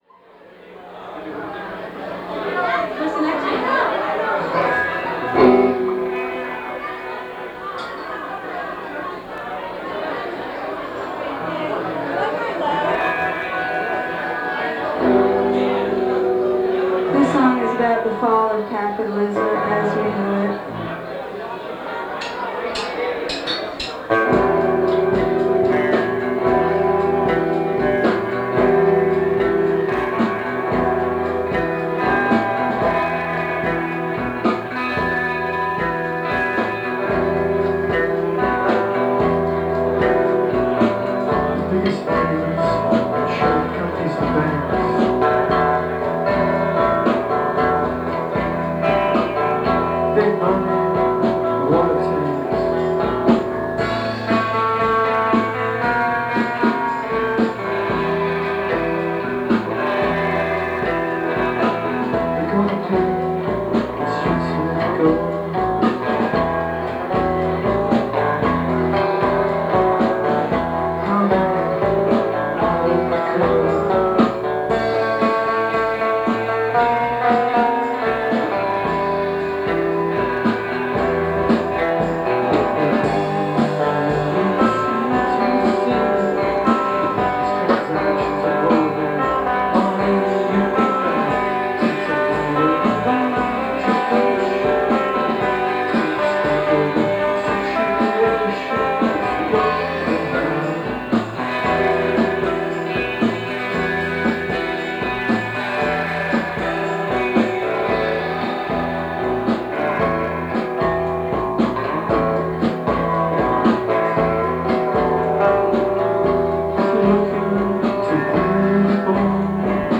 on guitar/vocals
JC Dobbs 5/31/95